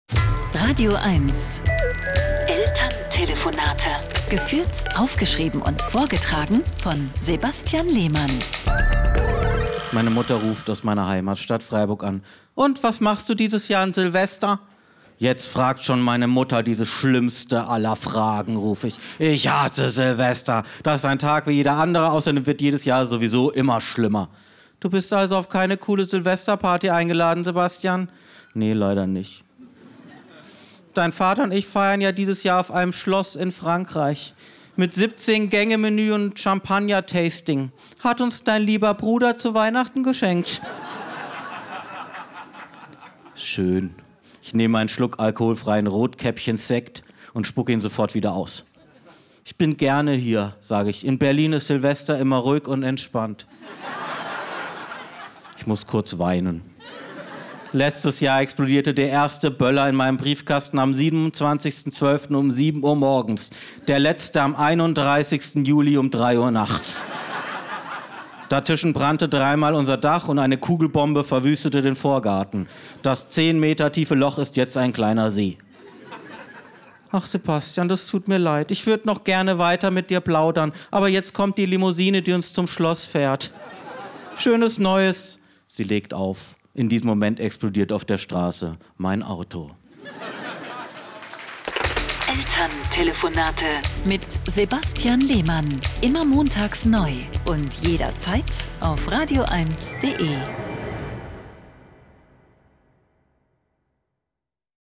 Deswegen muss er oft mit seinen Eltern in der badischen Provinz telefonieren.
Comedy